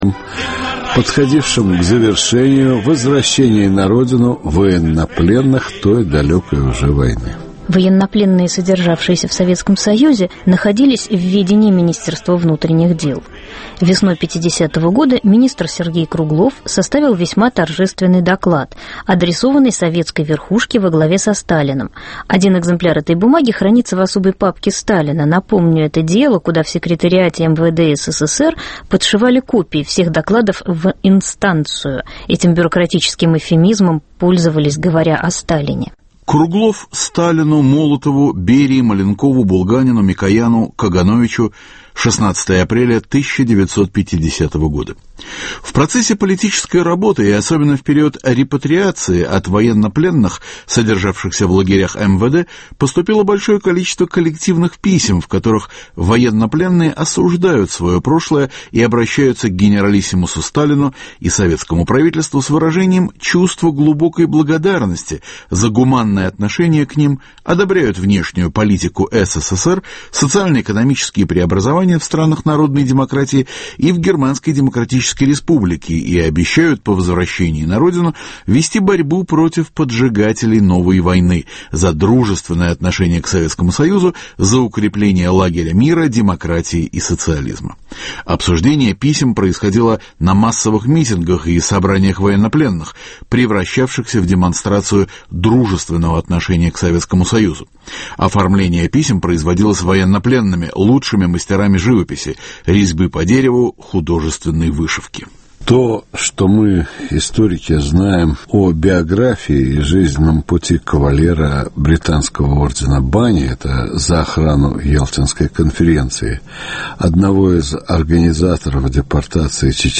В программе "Документы прошлого" передача о пропагандистских письмах из советского плена - "Из России с любовью".